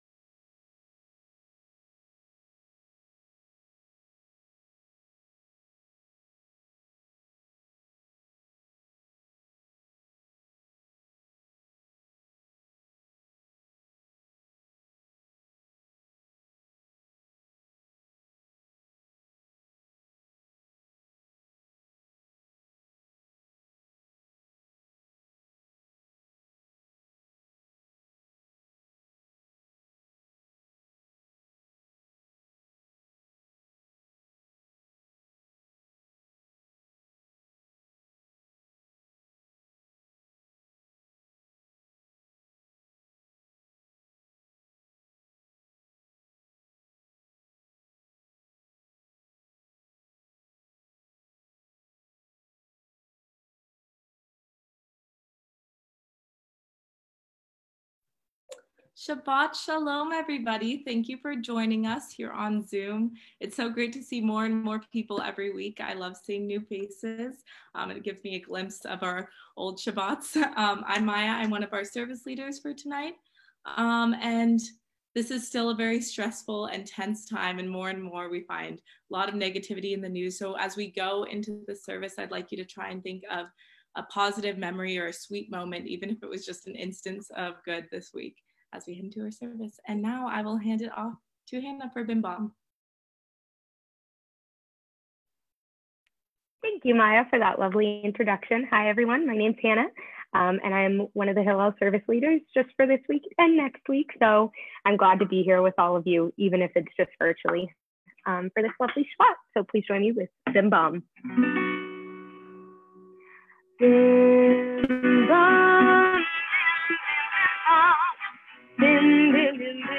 Santa Barbara Hillel May 29 Shabbat Facebook Livestream (untitled)